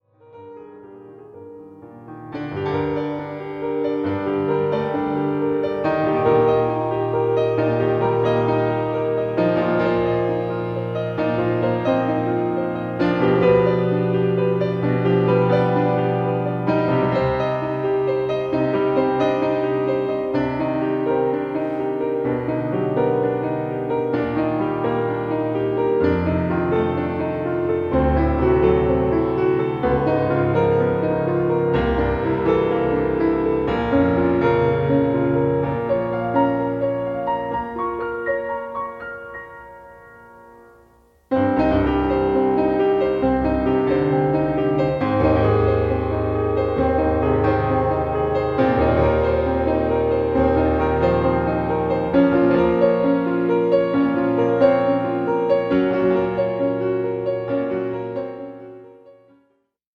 ピアノ
ボーカル